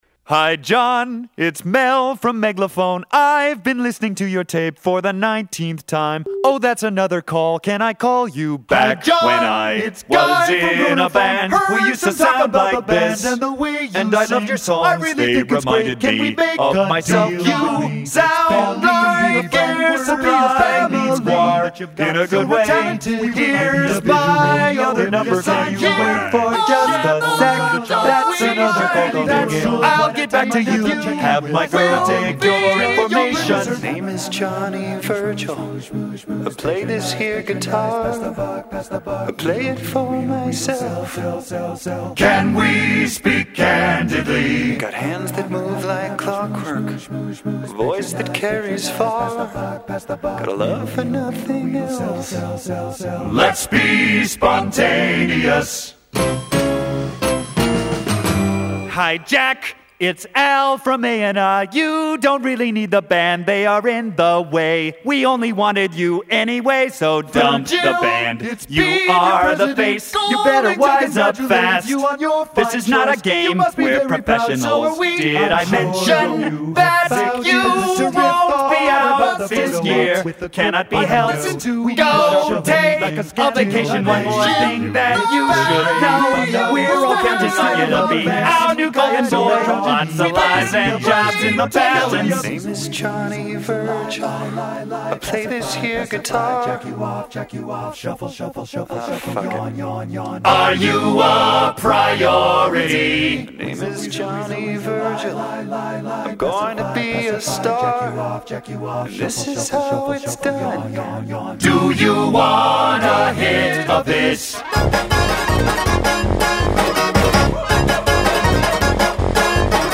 a Gentle Giant-influenced concept album that featured the
It's a brilliant piece of a cappella fugal writing.